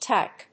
/tάɪk(米国英語)/